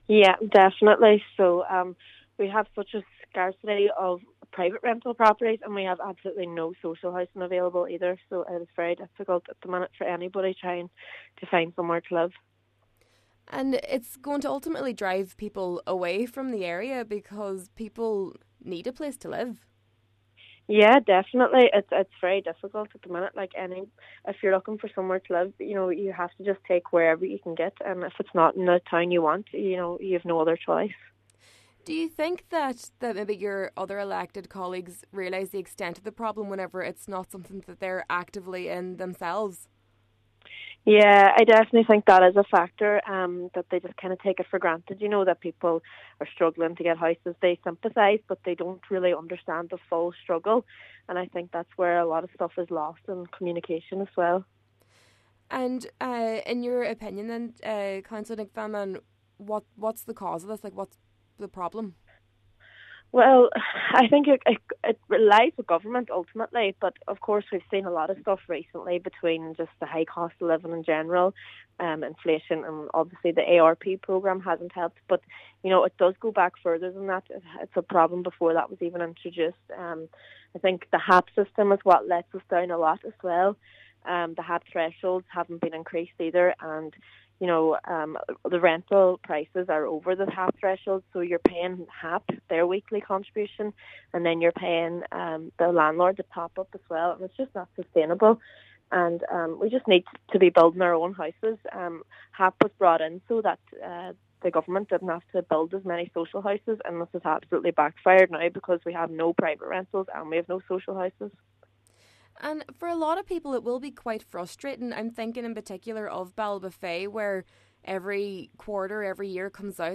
She says while some blame can be attributed to the ARP program and the cost of living crisis, she believes there is more to it: